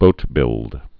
(bōtbĭld)